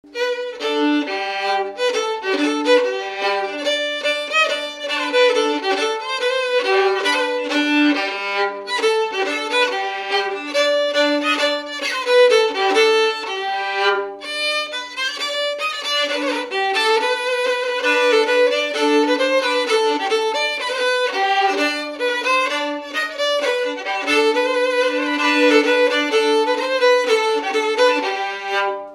Scottish
Résumé instrumental
danse : scottish (autres)
Enquête Arexcpo en Vendée
Pièce musicale inédite